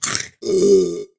add fight sounds
Eat.ogg